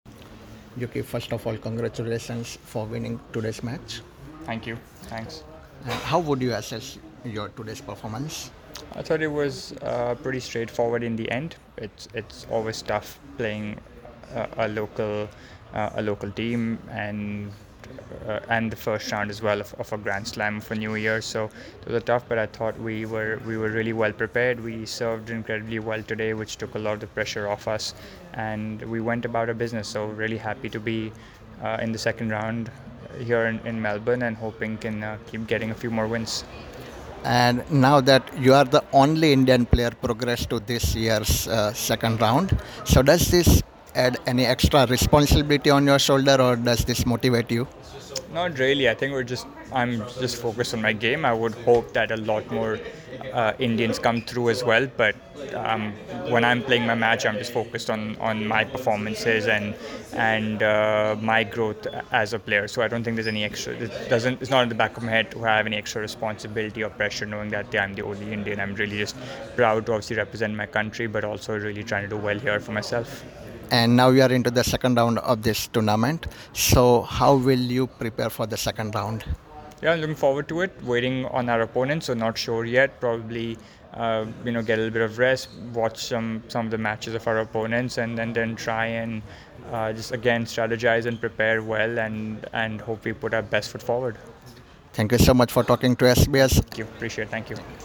Indian tennis player Yuki Bhambri, along with his Swedish partner Andre Goransson, progressed to the second round of the Australian Open men’s doubles competition after defeating Australia’s James Duckworth and Cruz Hewitt in straight sets, 6–3, 6–4. Yuki spoke with SBS about his performance and his upcoming matches in the tournament.
મેચ બાદ યુકીએ SBS Gujarati સાથે વાત કરી હતી.